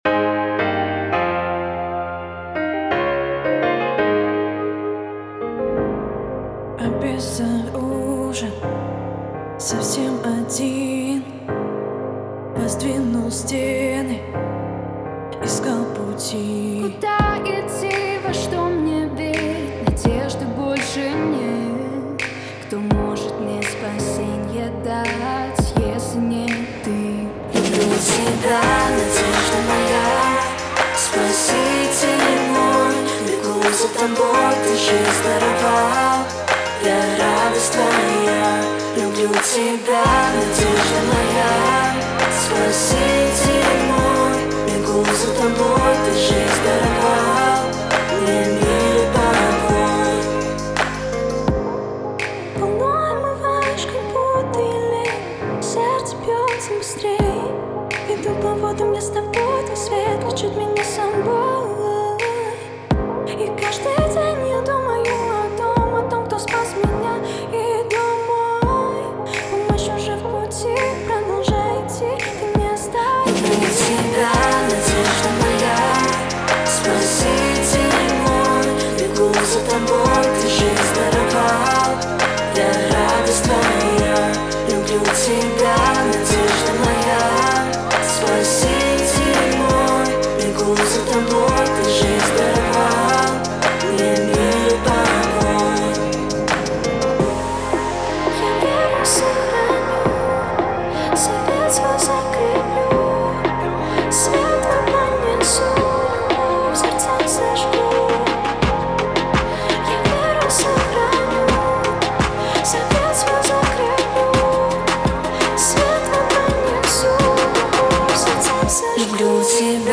45 просмотров 76 прослушиваний 3 скачивания BPM: 163